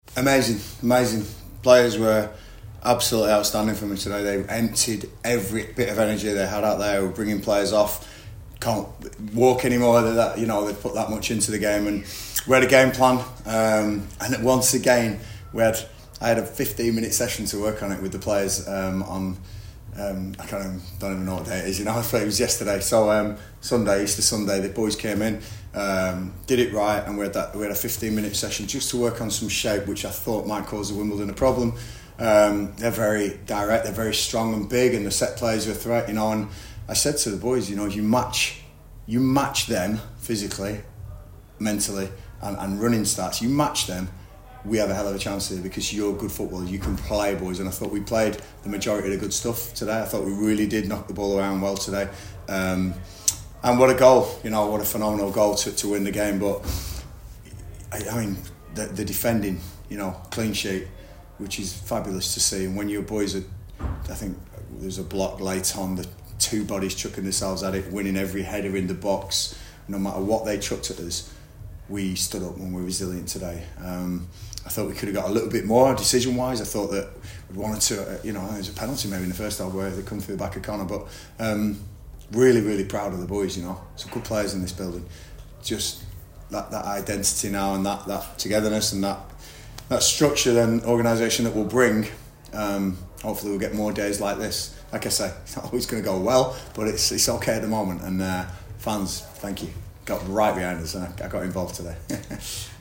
LISTEN: Gillingham manager Gareth Ainsworth told us what he thought of their match against AFC Wimbledon - 22/04/2025